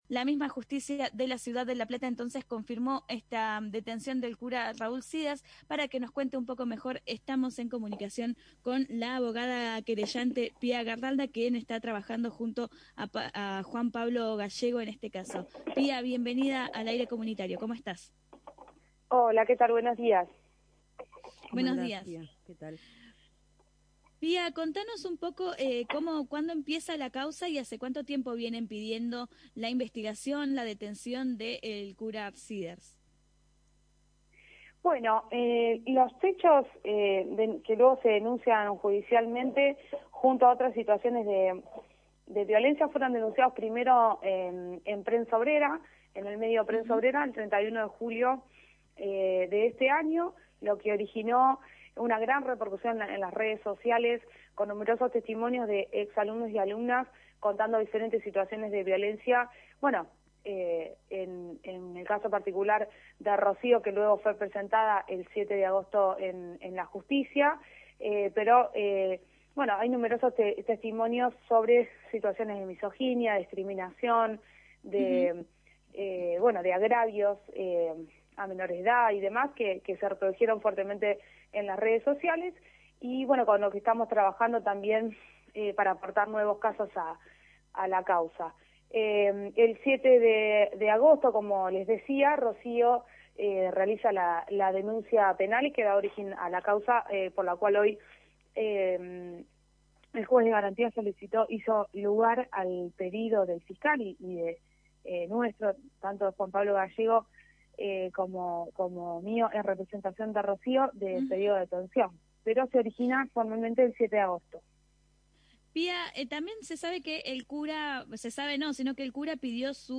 En Achatame la curva entrevistamos